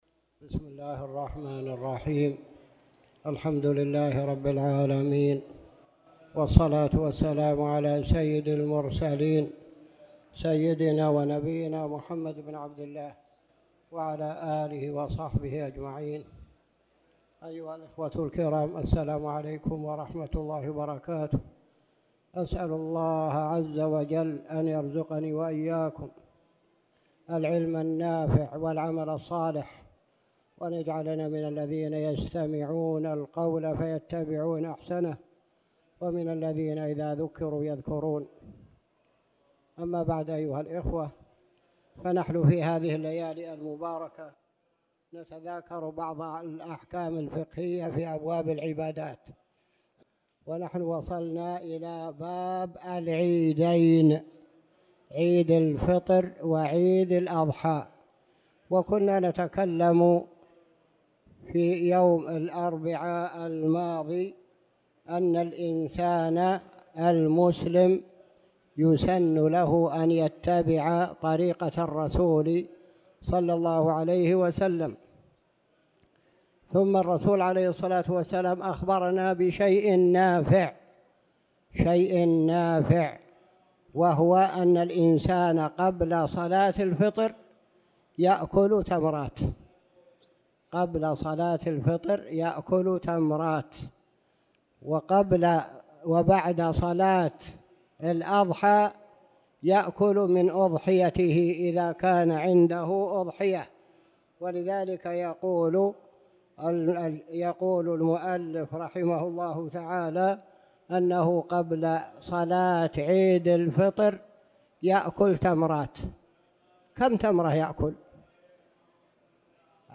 تاريخ النشر ٢١ شعبان ١٤٣٧ هـ المكان: المسجد الحرام الشيخ